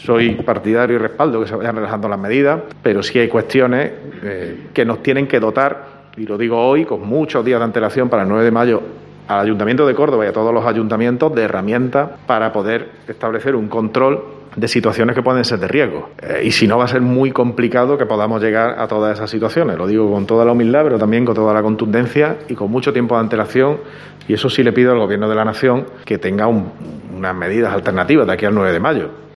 En una rueda de prensa, junto a la primera teniente de alcalde, Isabel Albás (Cs), el regidor ha asegurado que "hasta ahora" la Junta Andalucía con todos los consejeros "ha demostrado que han estado a la altura de las circunstancias en esta pandemia" con las medidas que han ido tomando.